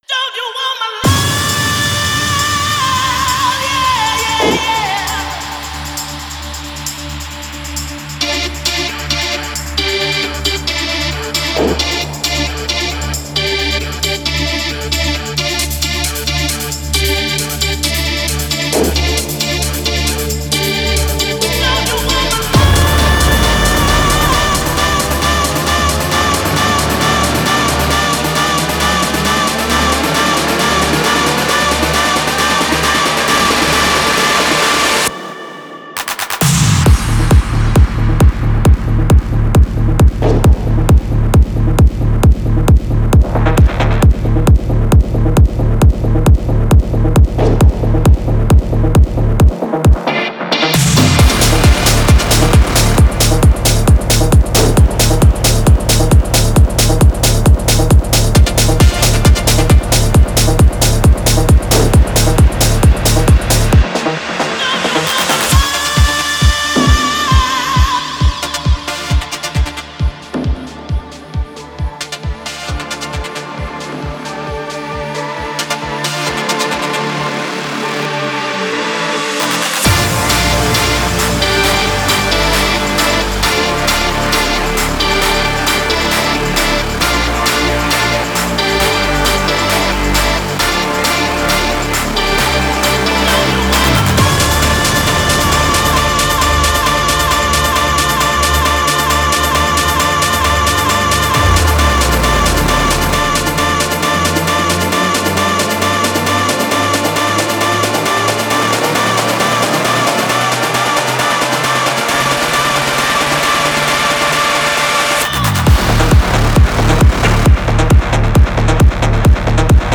энергичная композиция в жанре EDM